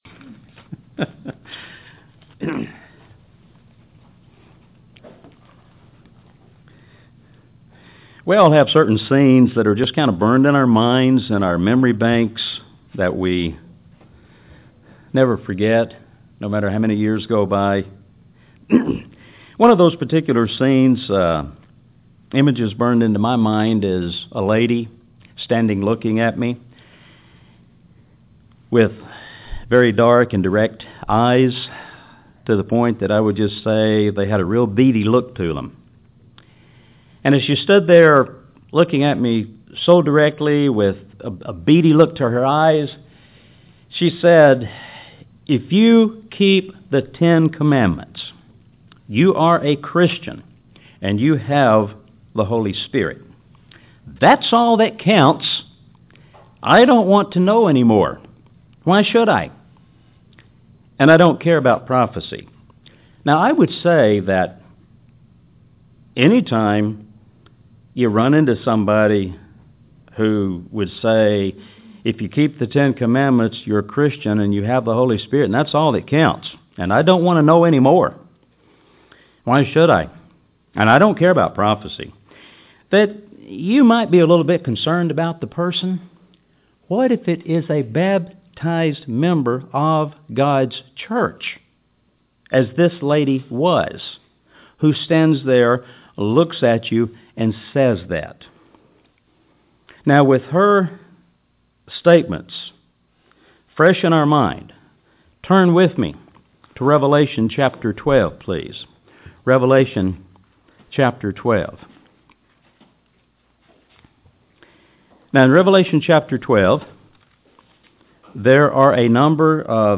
We're part of a work that has great responsibility. In this sermon, we'll look at basic beliefs about the reason for our creation and important dots we need to connect in our spiritual lives.